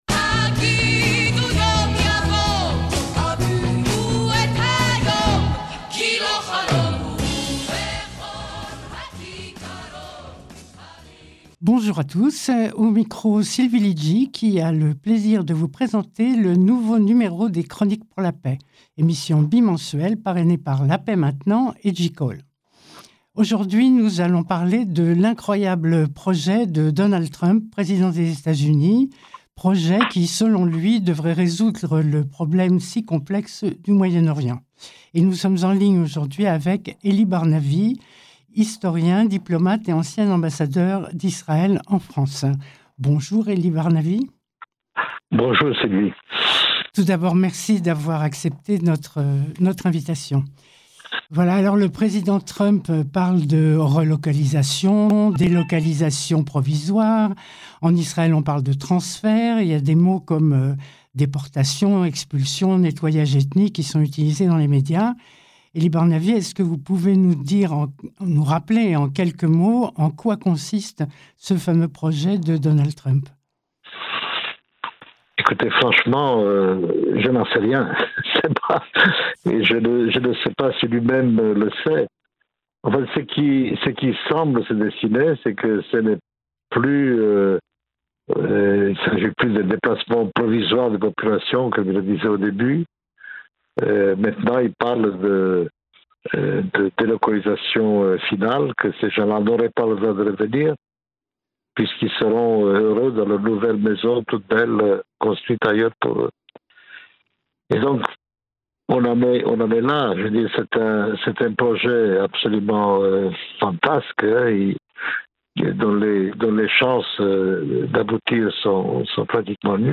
Entretien avec Elie Barnavi sur les récentes déclarations de Donald Trump concernant Gaza - JCall
CLIQUER SUR LA FLÈCHE (LIEN) CI-DESSOUS POUR ÉCOUTER L’INTERVIEW SUR RADIO SHALOM (94.8 fm)